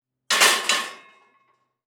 Metal_15.wav